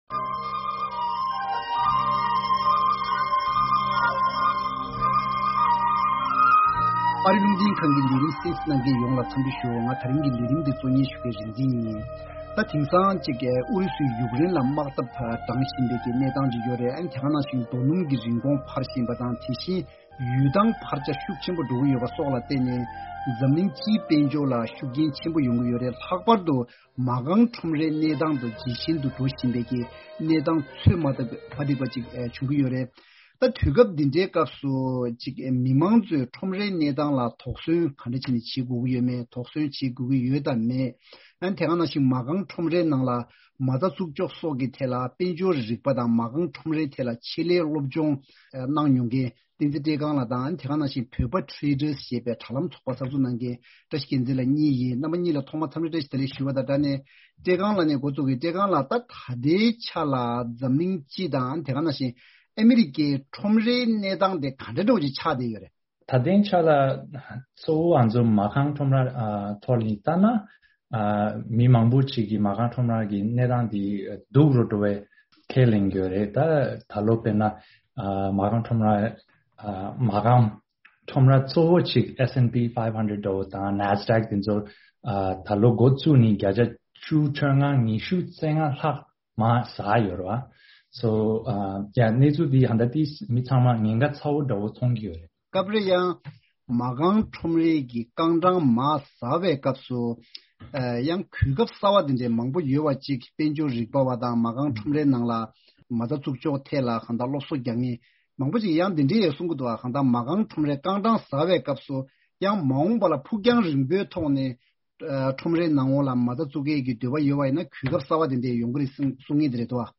ཐེངས་འདིའི་བགྲོ་གླེང་མདུན་ཅོག་ལས་རིམ་གྱིས་མ་རྐང་ཁྲོམ་རེའི་གནས་སྟངས་དང། དེ་ལ་གཞིགས་ནས་མ་རྐང་ཁྲོམ་རའི་ནང་མ་རྩ་འཛུགས་ཕྱོགས་སོགས་ཀྱི་ཐད་གླེང་མོལ་ཞུས།